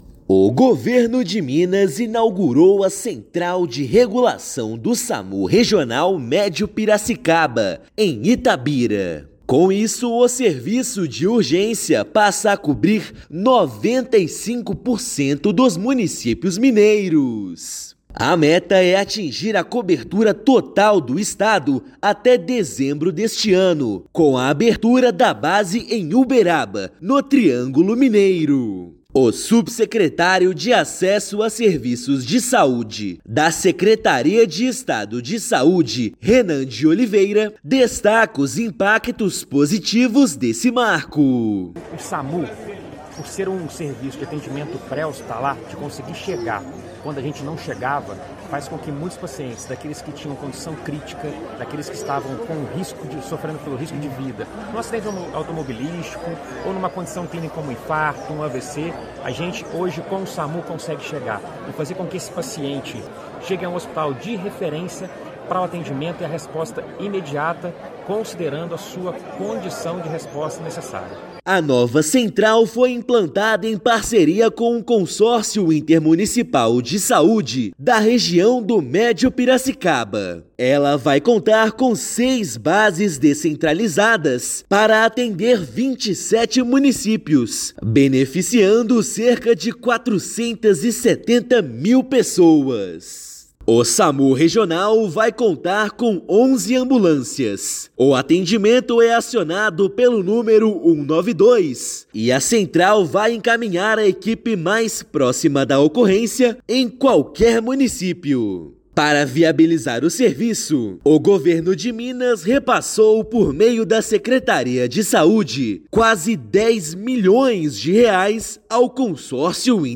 [RÁDIO] Minas atinge 95% de cobertura do Samu com nova base em Itabira
Previsão é alcançar 100% até dezembro, com a inauguração da base em Uberaba. Ouça matéria de rádio.